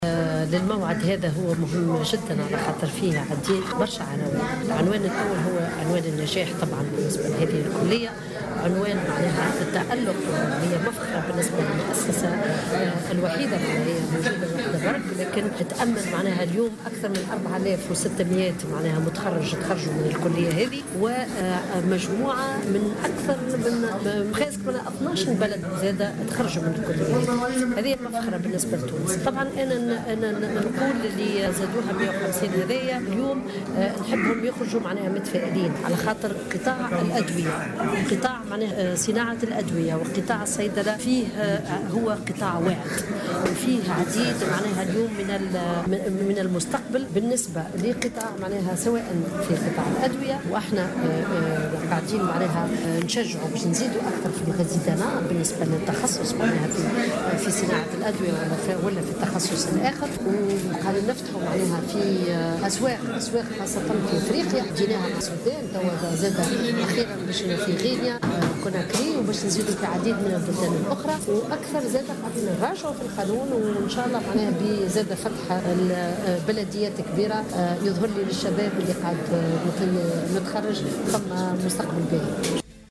وأضافت في تصريح لمراسل "الجوهرة اف أم" على هامش حفل تخرج 150 صيدلانيا من كلية الصيدلة في المنستير، أن قطاع صناعة الأدوية واعد في تونس وهناك تشجيع على هذا الاختصاص.